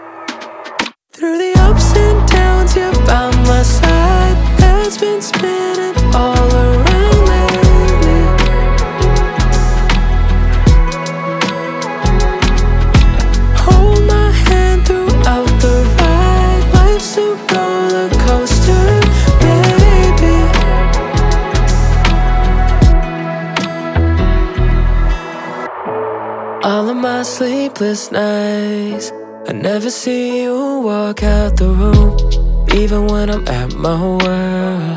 2021-11-19 Жанр: Поп музыка Длительность